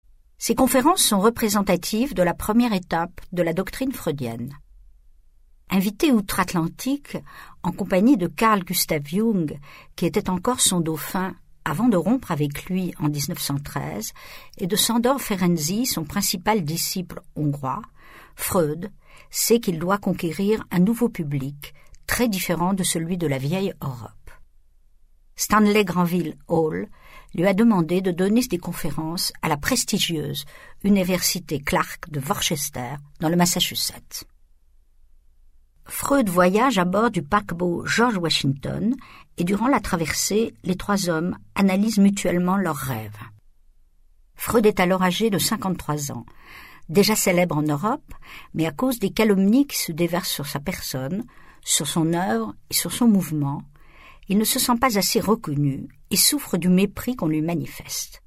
François Marthouret prête toute son intelligence à la lecture de ce texte qui se voulut, d’abord, une présentation pédagogique des concepts fondateurs de la psychanalyse.